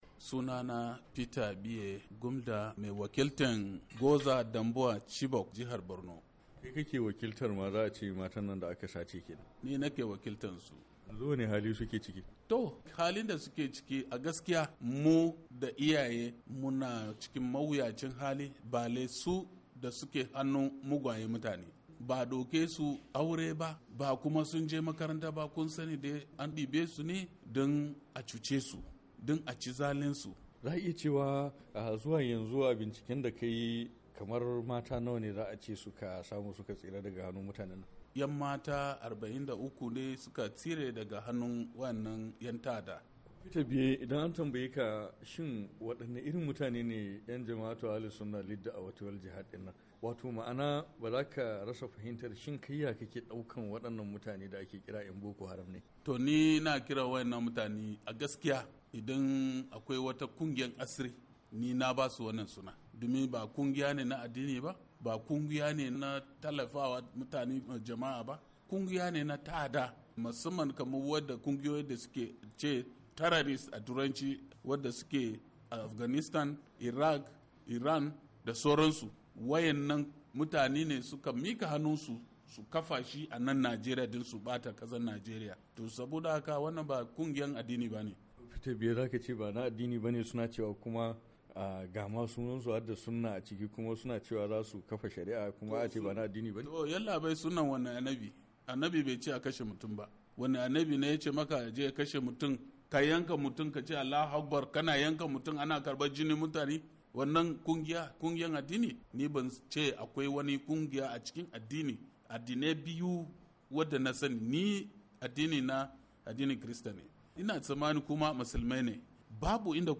A wata fira da wakilin Muryar Amurka yayi da wani dan majalisa ya bayyana yadda dajin Sambisa yake wanda ko a tsakar rana ba'a iya shiga cikinsa ba tare da fitila ba.